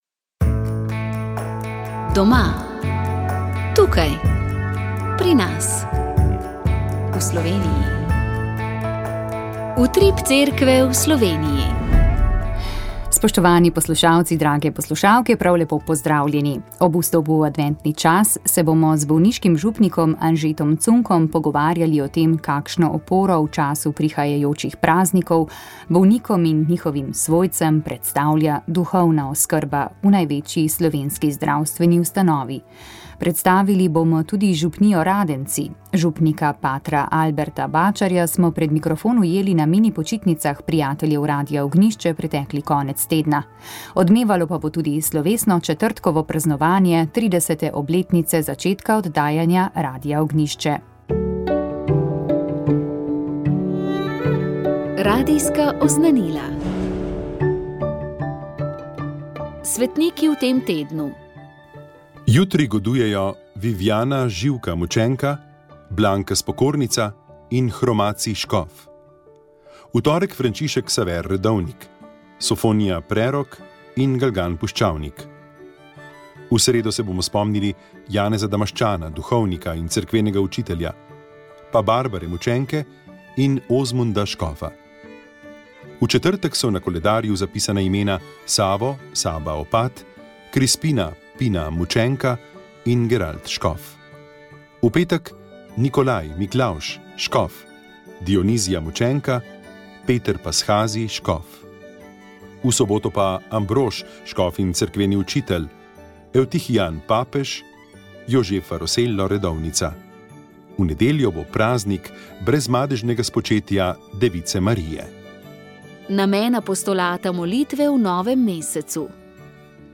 Slišali ste, kaj je prinesel teden v Cerkvi na Slovenskem, tokratni poudarek pa je bil na nedavnem slovesnem posvečenju treh novih diakonov, med katerimi bo eden naslednje leto postal duhovnik. Udeležili smo se tudi znanstvenega simpozija o samostanu dominikank v Studenicah